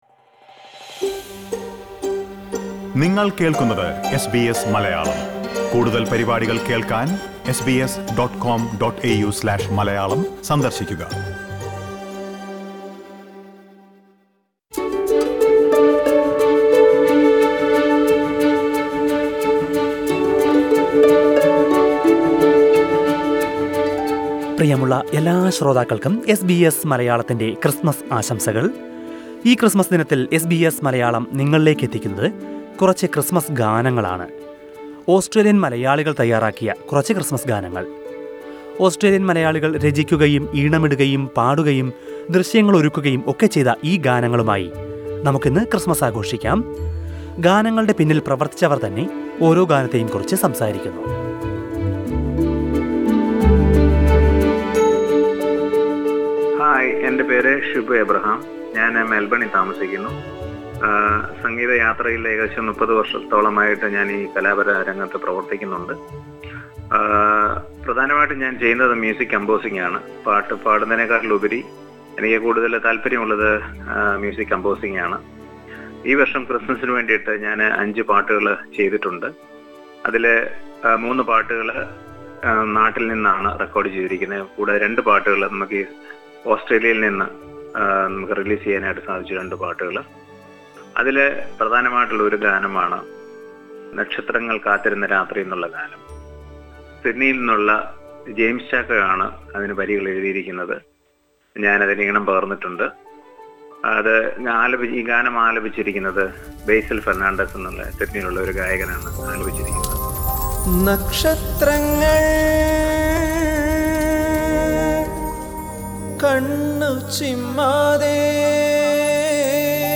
ഈ ക്രിസ്ത്മസ് കാലത്ത് നിരവധി ഓസ്ട്രേലിയൻ മലയാളികളാണ് ക്രിസ്ത്മസ് ഗാനങ്ങൾ പുറത്തിറക്കിയിരിക്കുന്നത്. അവയിൽ ചിലതിനെക്കുറിച്ച് ഗാനങ്ങൾക്ക് പിന്നിൽ പ്രവർത്തിച്ചവർ വിവരിക്കുന്നത് കേൾക്കാം...